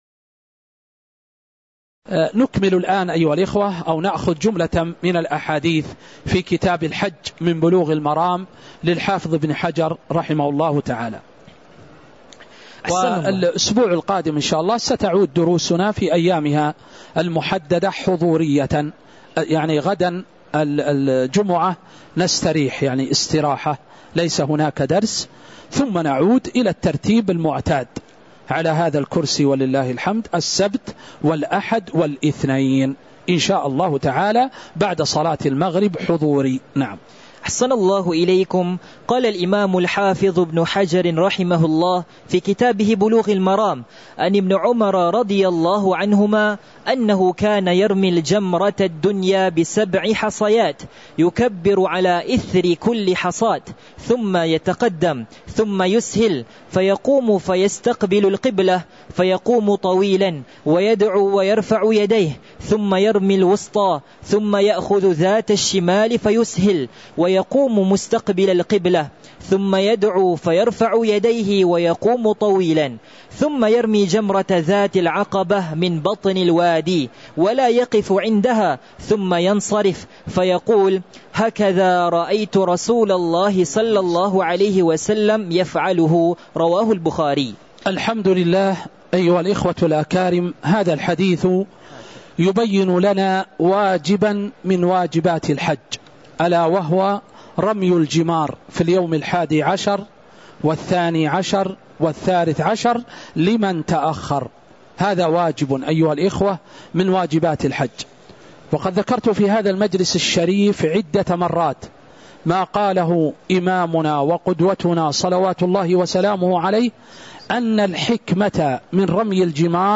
تاريخ النشر ٢٩ ذو القعدة ١٤٤٥ هـ المكان: المسجد النبوي الشيخ